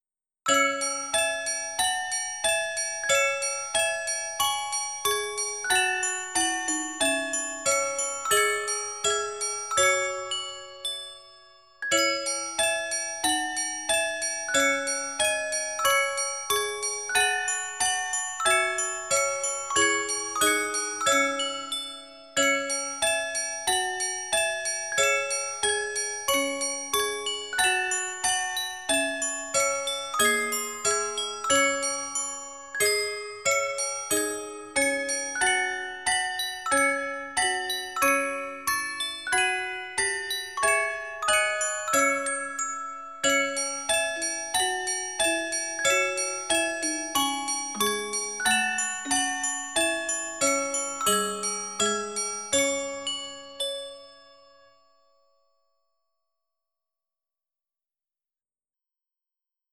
Dark arrange version of famous works